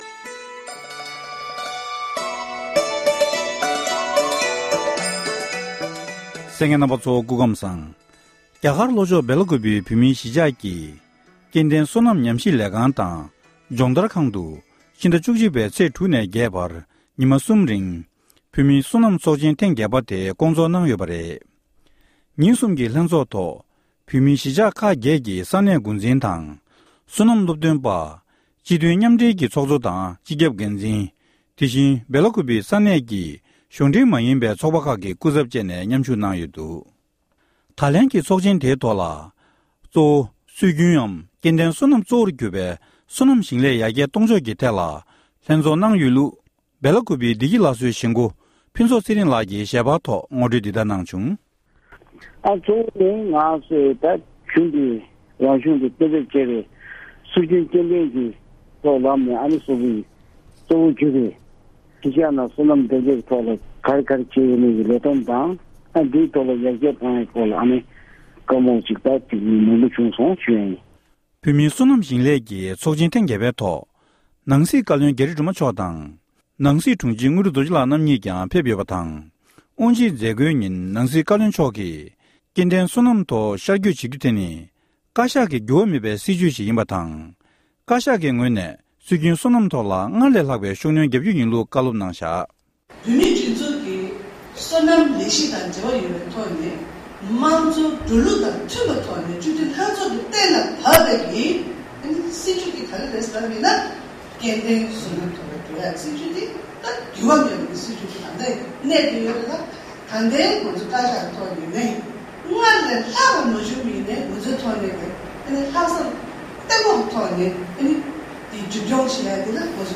བཀའ་འདྲི་ཞུས་པ་ཞིག་གསན་རོགས༎